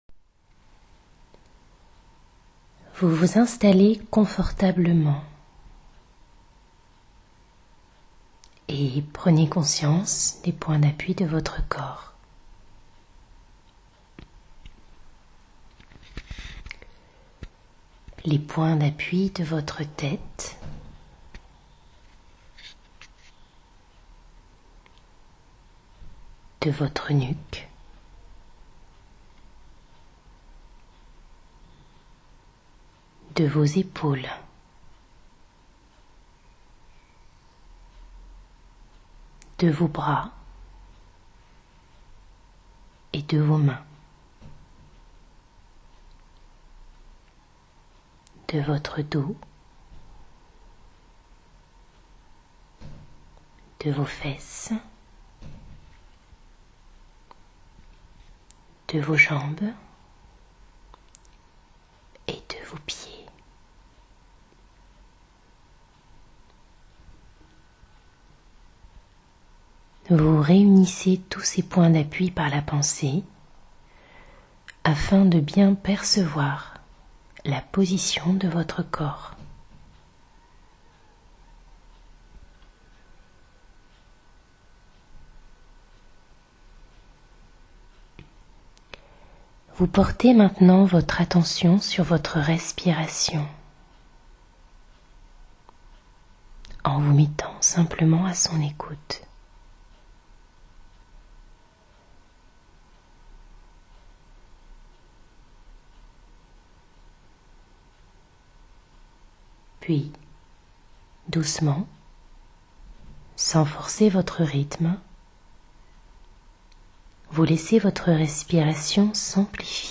sophro-relaxation-guidee1.mp3